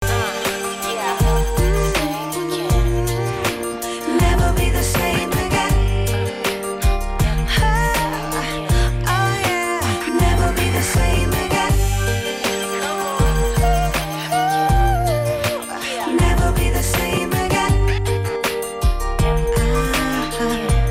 Качество приёма FM-радиостанций заслуживает хорошей оценки.
Тюнер продемонстрировал хорошее качество звука в FM-режиме (